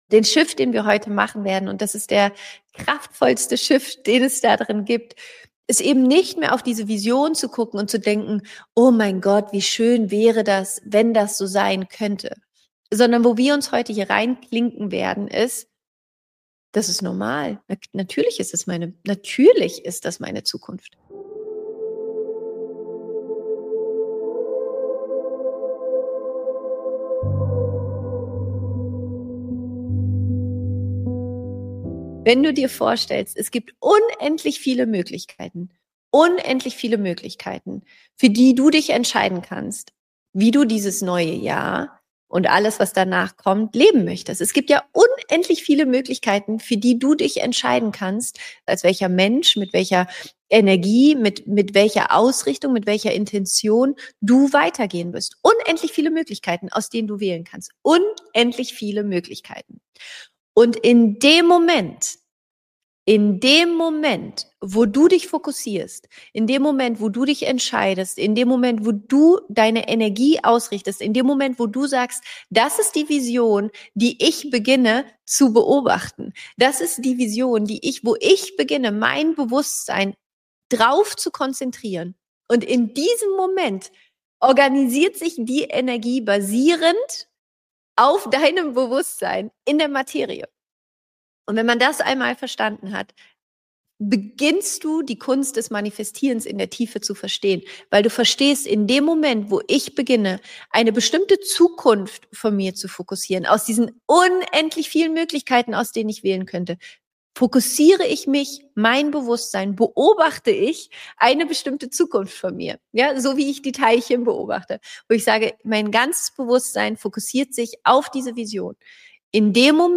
In dieser Special Folge erfährst du, wie du aus den 0,1% alter Möglichkeiten aussteigst, dich für unendliche neue Möglichkeiten öffnest und deine Vision durch eine kraftvolle 25 Minuten Meditation zur gelebten Realität machst.
Diese Folge ist ein bisher unveröffentlichter Ausschnitt aus dem New Years Magic Live (Januar 2026).